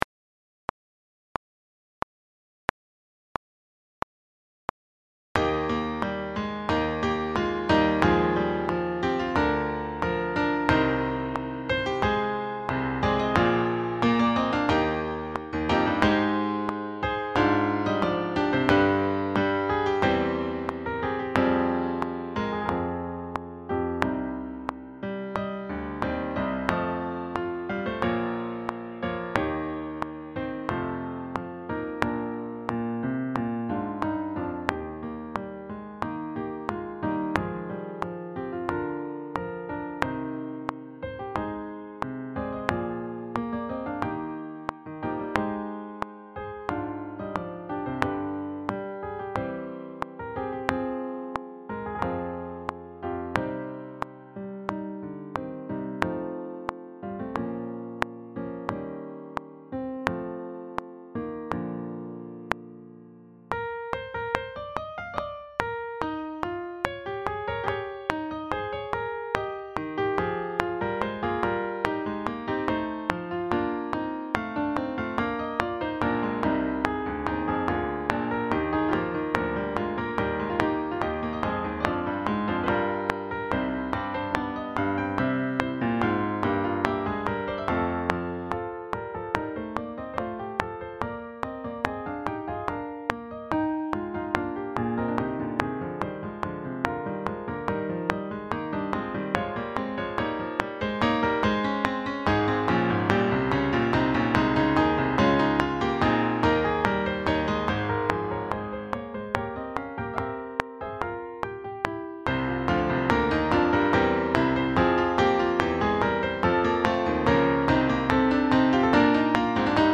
Sax Sextets
SAATBbDuration:
It tells the love story between Cadmus, King of Thebes, and Hermione, daughter of Venus and Mars. This arrangement of the overture is for six saxes.
Backing track